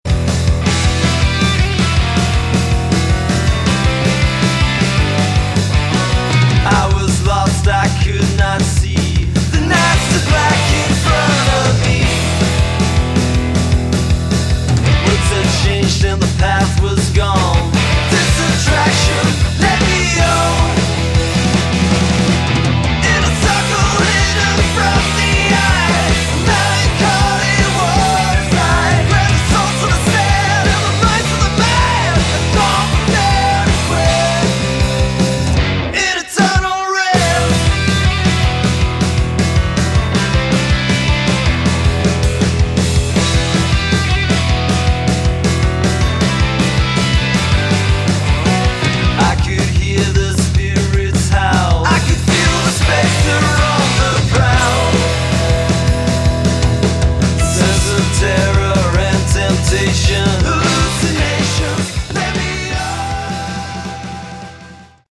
Category: Hard Rock
guitar, vocals
drums